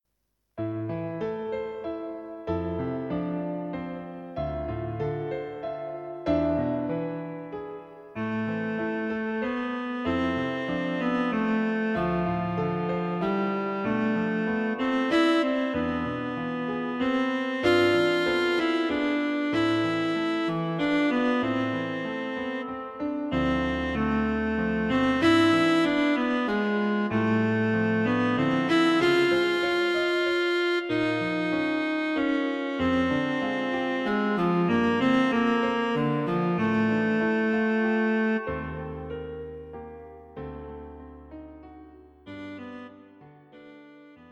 Cello and Piano A charming little piece for Cello and Piano.
Plenty of busy rhythms and harmonies to keep it interesting.